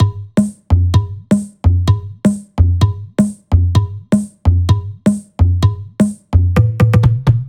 VFH3 Mini Kits Drums